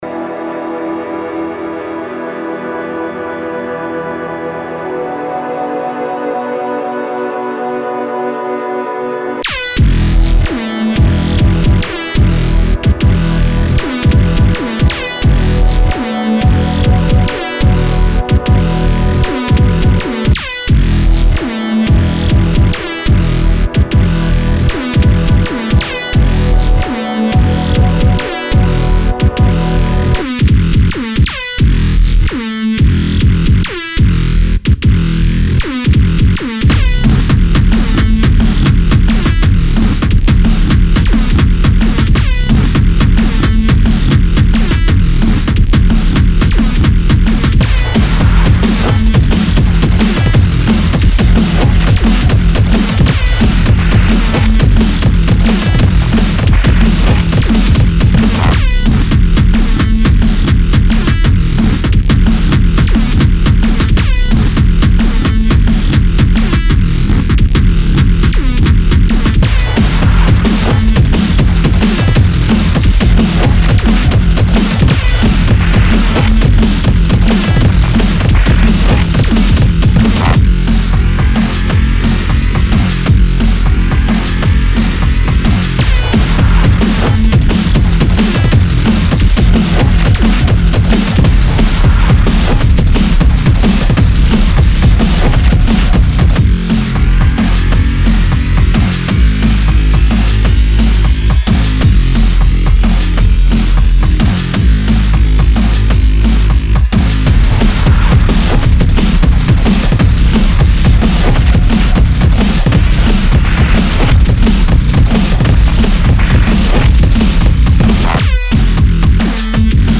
HARDDRUM&BASS